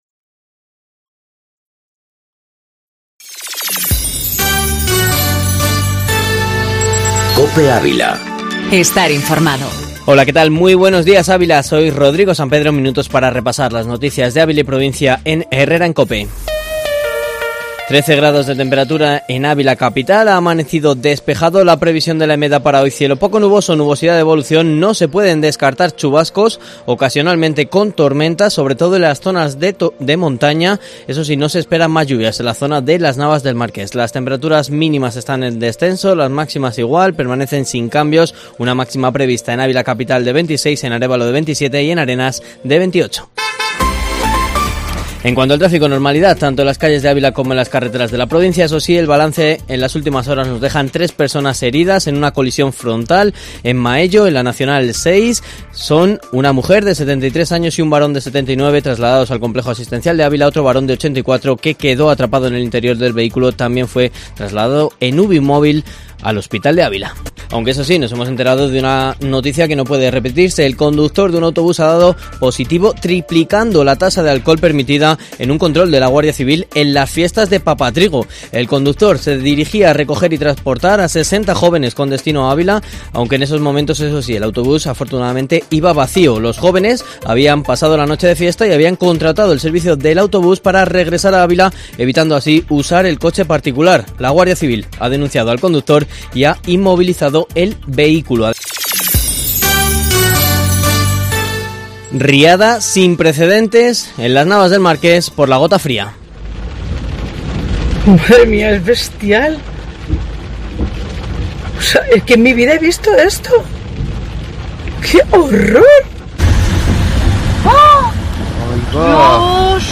Informativo matinal Herrera en COPE Ávila 27/08/2019